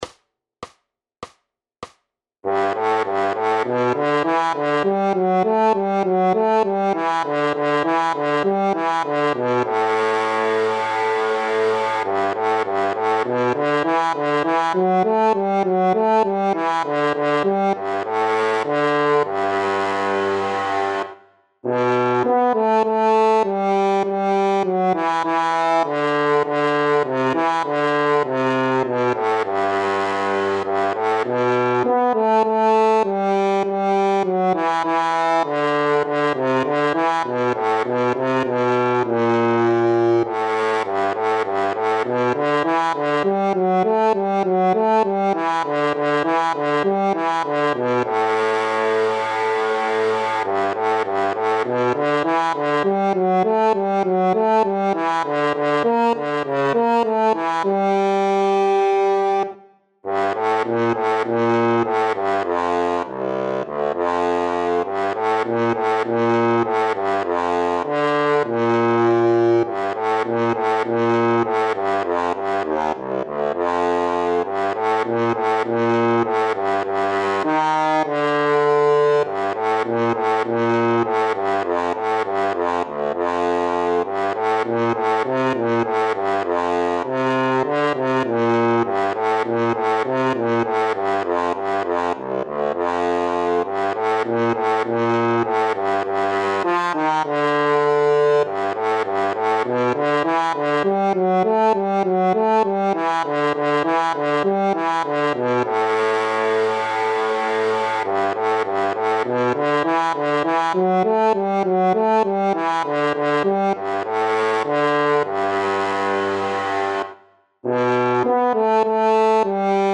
Noty na lesní roh.
Aranžmá Noty na lesní roh
Hudební žánr Klasický